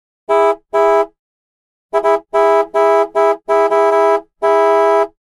Звуки клаксона
Звук автомобильного сигнала